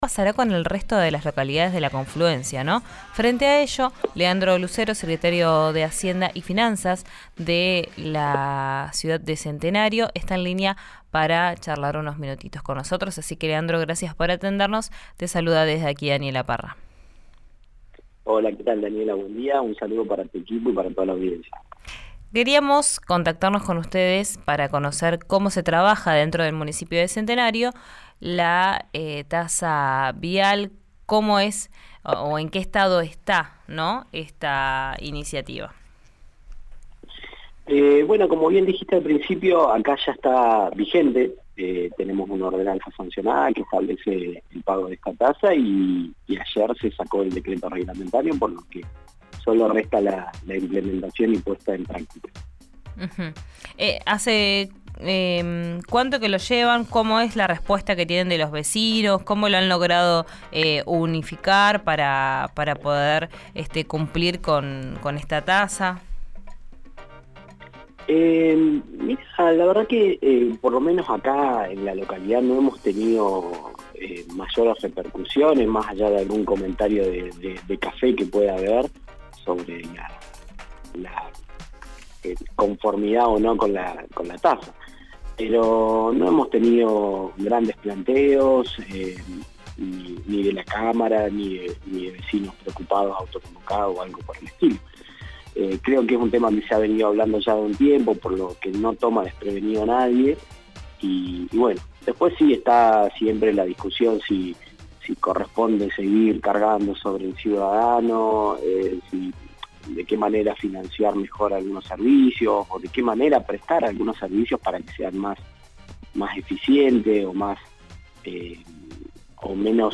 El secretario de Hacienda y Finanzas de Centenario habló sobre los detalles de esta tasa en RÍO NEGRO RADIO.
Escuchá a Leandro Lucero, secretario de Hacienda y Finanzas de Centenario, en RÍO NEGRO RADIO: